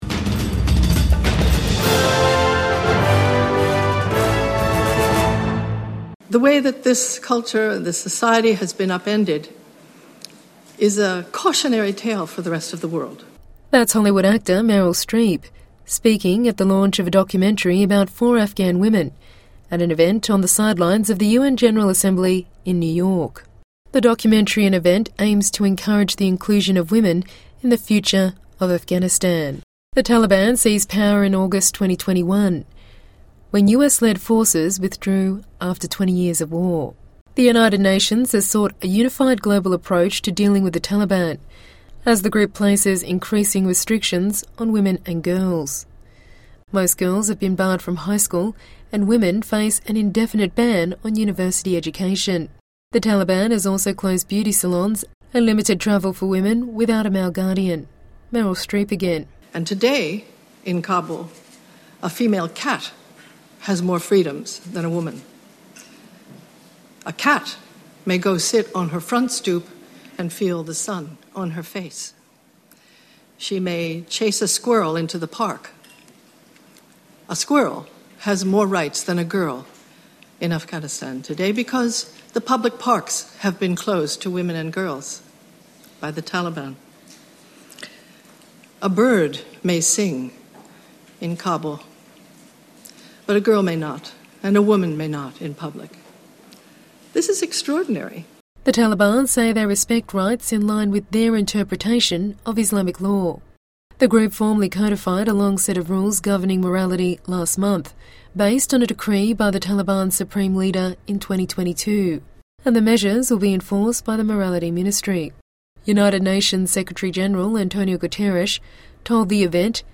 Hollywood actor Meryl Streep speaking at the launch of a documentary about four Afghan women at an event on the sidelines of the U-N General Assembly in New York.